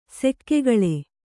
♪ sekkegaḷe